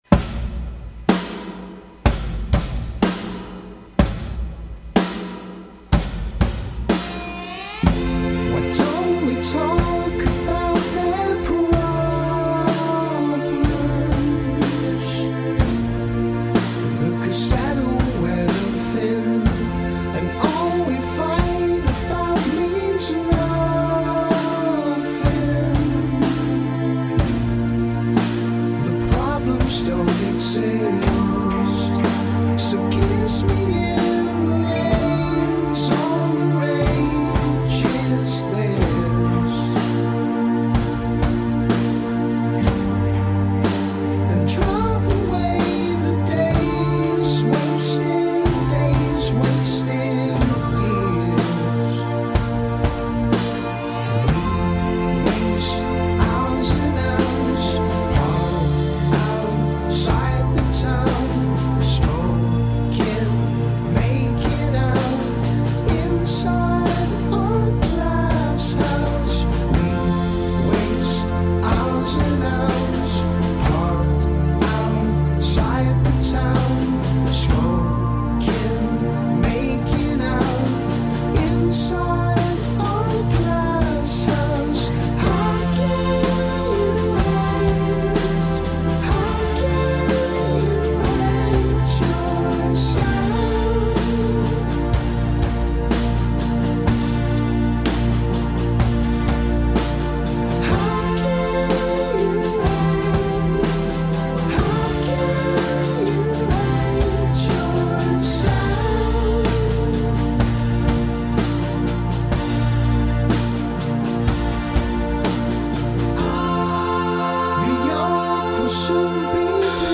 1. The arena-sized drums that open the track.
2. The mile-high backup vocals at 1.55.
3. The parade-into-the-sunset fadeout at the end.
Tags2010s 2013 Britain holidays New Year's Rock